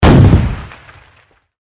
Cannon impact 9.wav